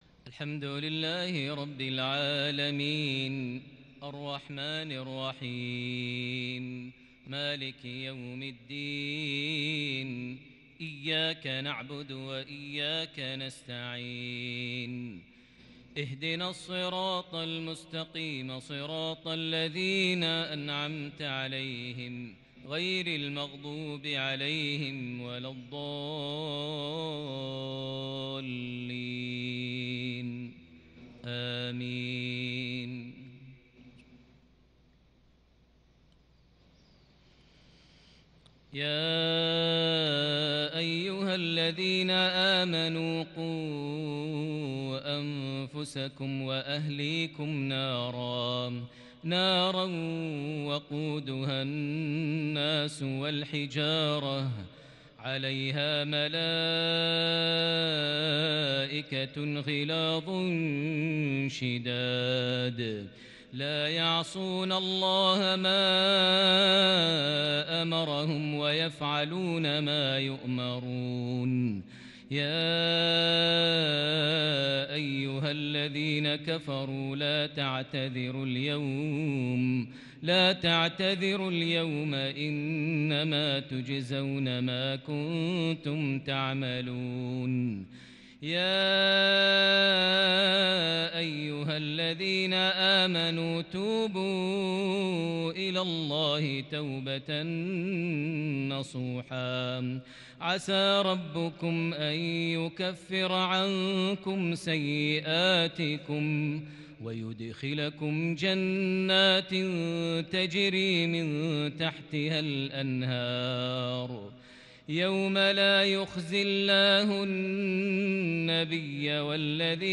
استفتاحيه جليله لتلاوة كرديه بديعة من خواتيم سورة التحريم | مغرب 7 محرم 1442هـ > 1442 هـ > الفروض - تلاوات ماهر المعيقلي